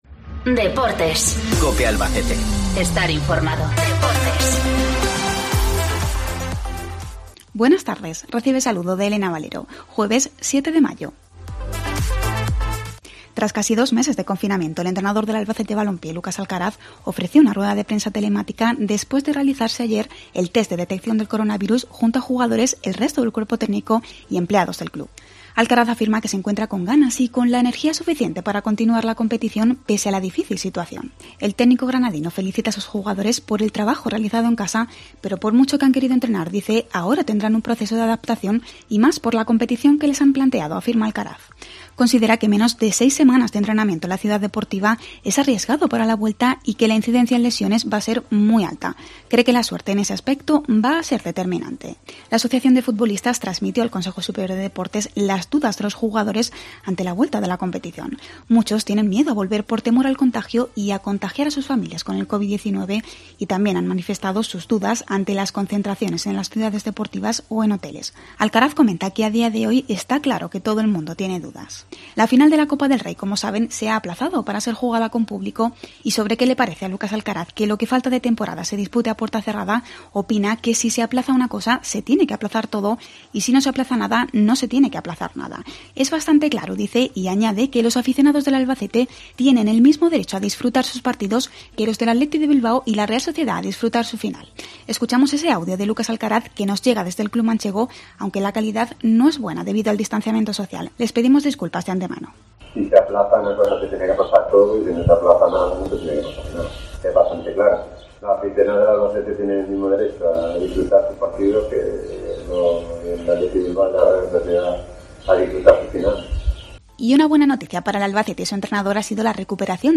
Además, entrevista